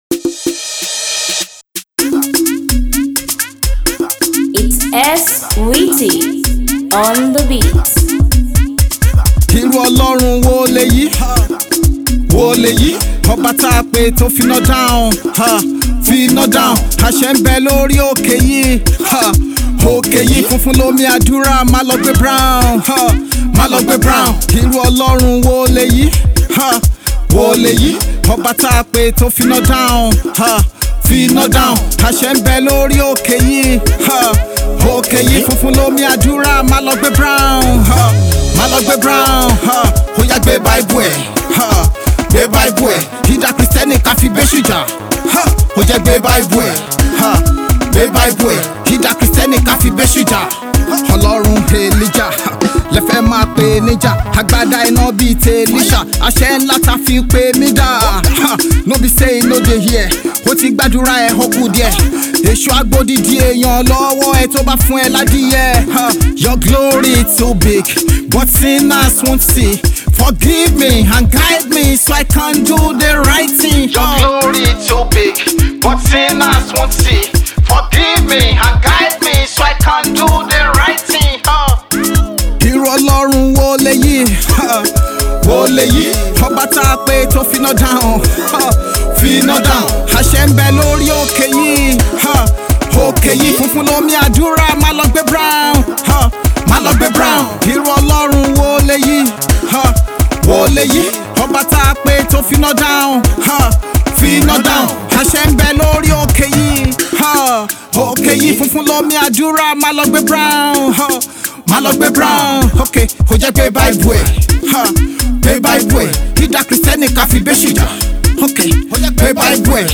street rated gospel afrobeats